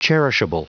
Prononciation du mot cherishable en anglais (fichier audio)
Prononciation du mot : cherishable